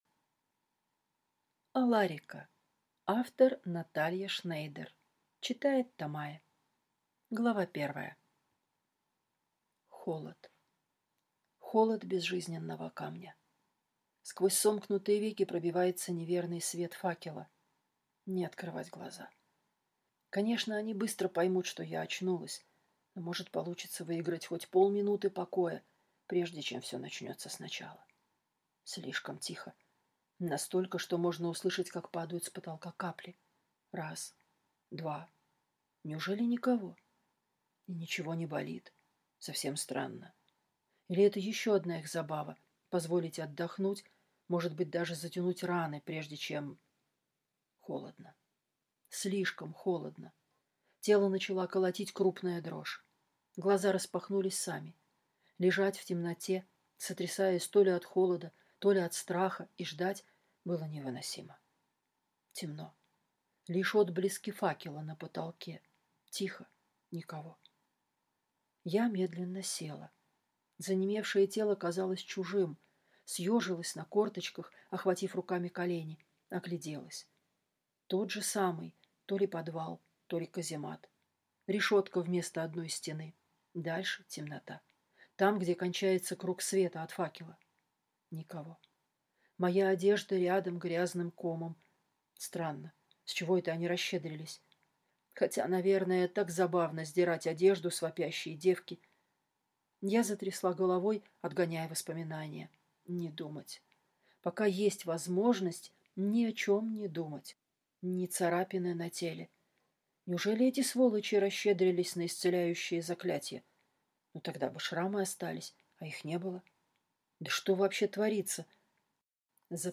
Аудиокнига Аларика | Библиотека аудиокниг